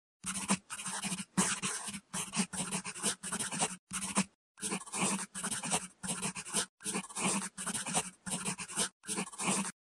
Шуршание пишущего карандаша